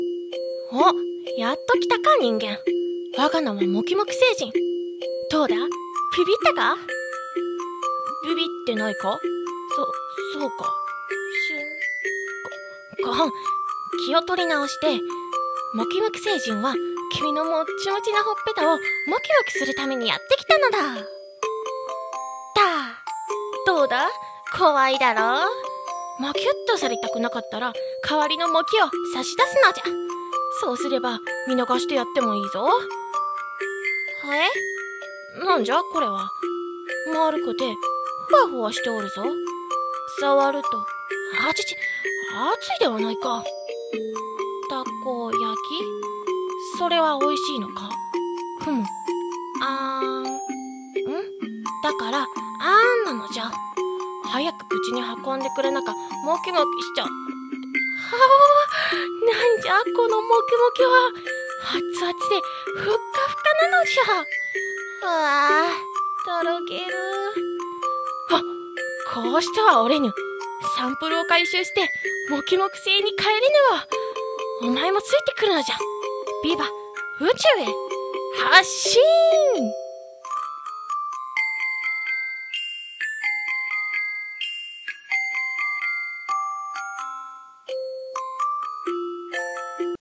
《オルゴール・BGM》
BGM:✡✡✡/オルゴール